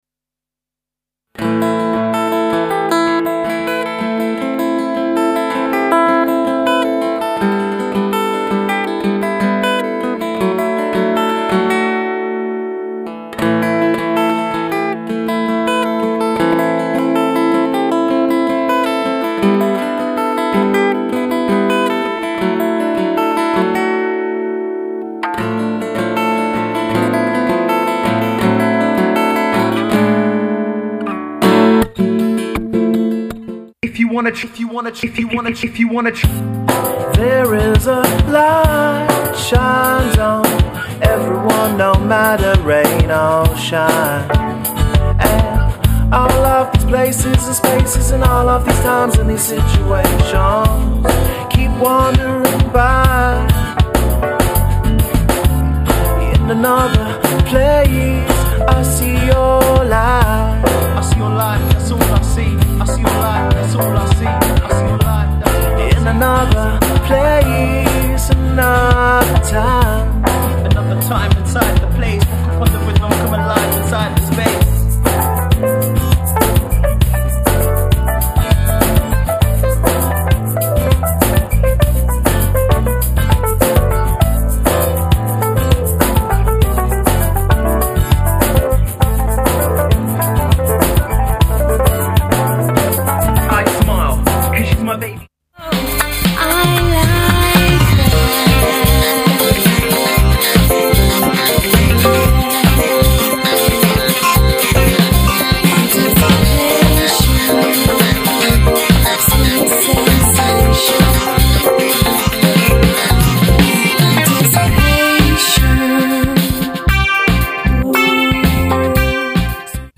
Acoustic, Funk, RnB, Dance